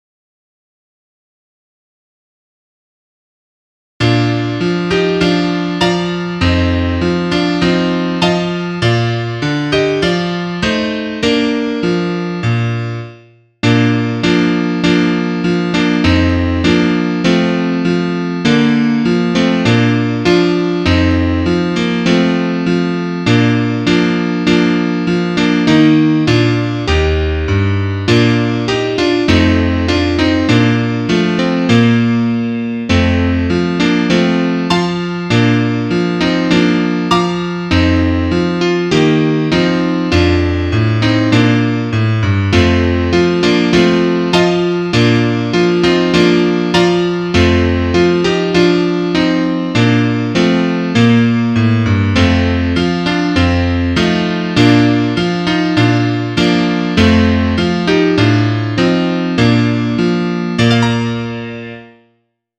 Trompete 1